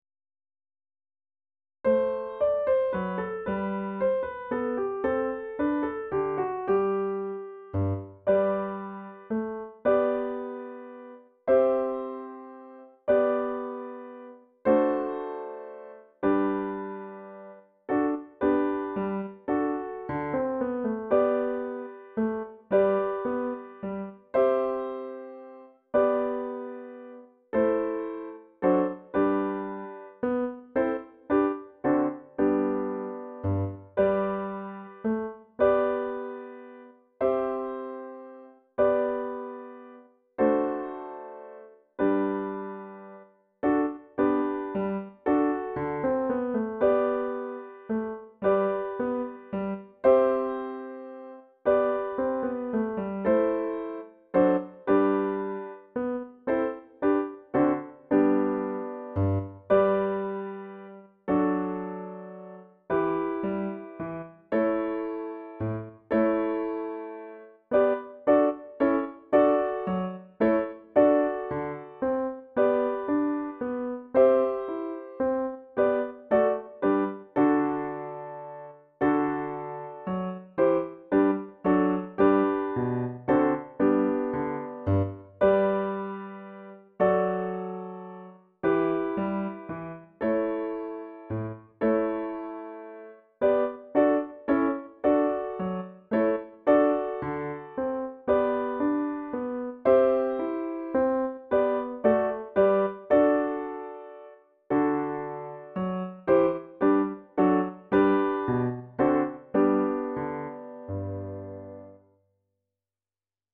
Menuet_-_PLAYBACK.mp3